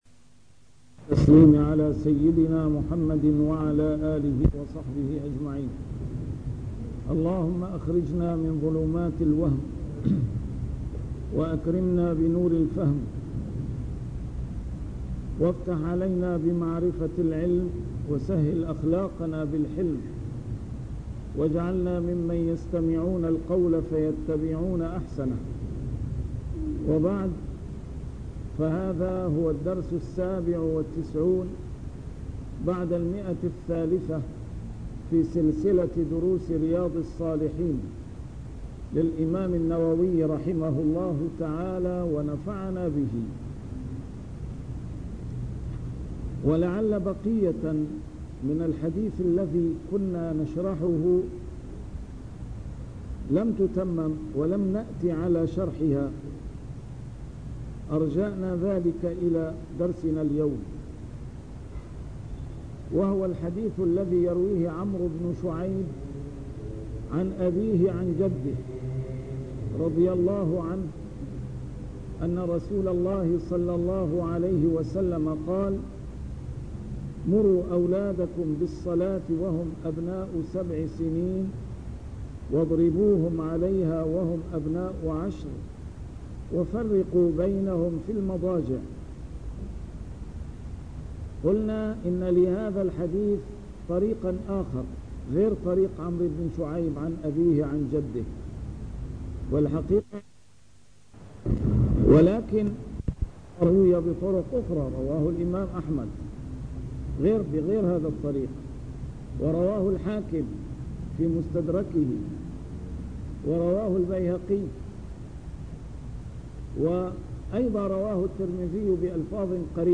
A MARTYR SCHOLAR: IMAM MUHAMMAD SAEED RAMADAN AL-BOUTI - الدروس العلمية - شرح كتاب رياض الصالحين - 397- شرح رياض الصالحين: أمر الرجل أهله بطاعة الله